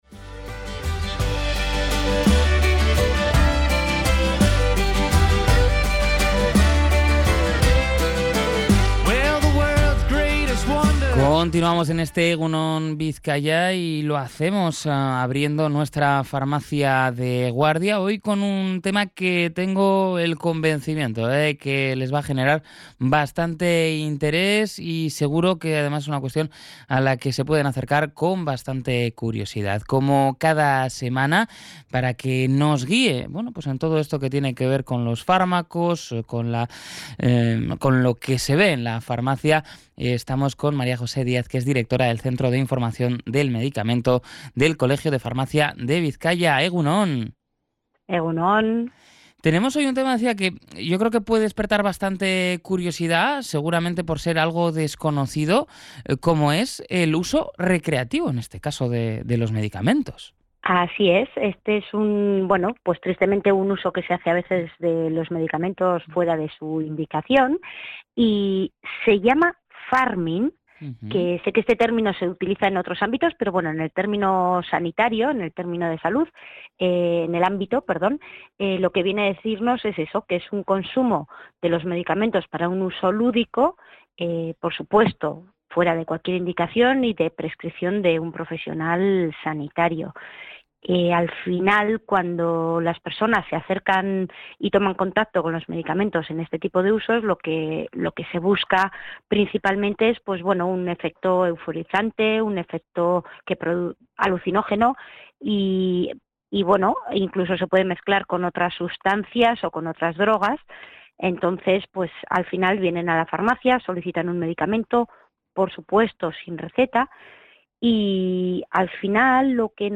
En la última edición de la sección «Farmacia de Guardia» dentro del programa EgunOn Bizkaia, se ha abordado una tendencia creciente y alarmante: el pharming.